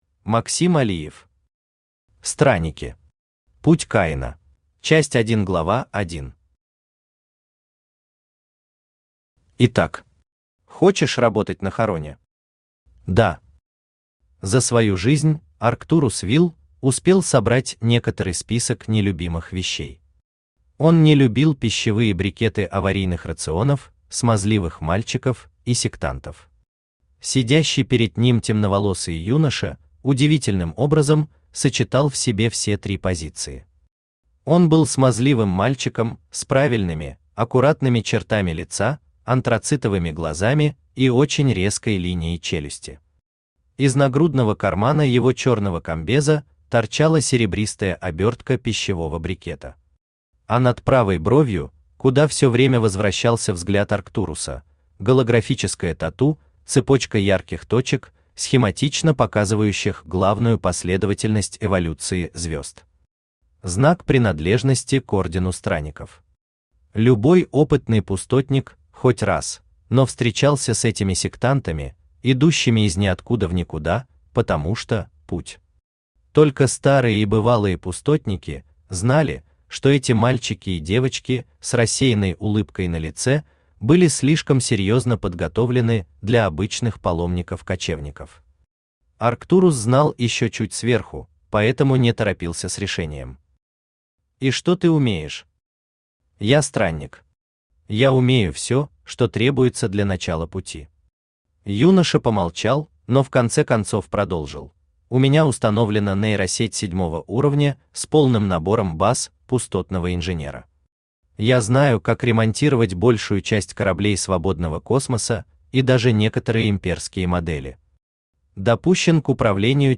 Аудиокнига Странники. Путь Каина | Библиотека аудиокниг
Путь Каина Автор Максим Алиев Читает аудиокнигу Авточтец ЛитРес.